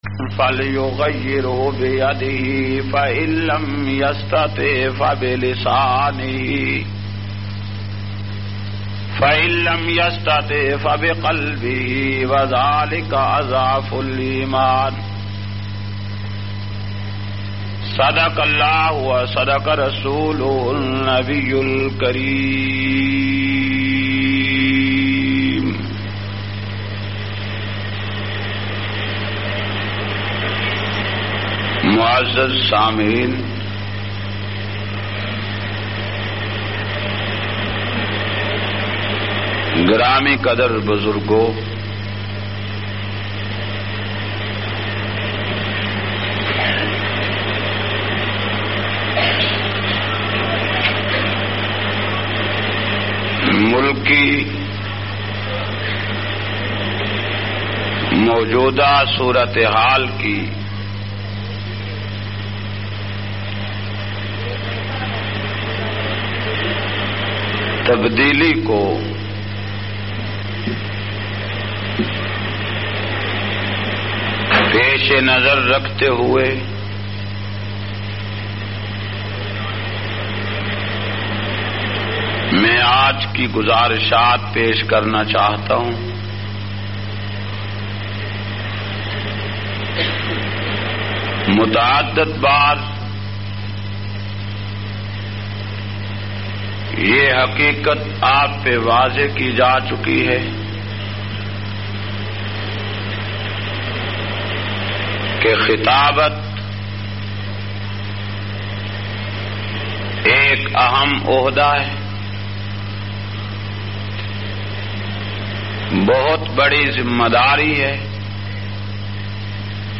337- Siyasi Inqilab Jumma khutba Jhang.mp3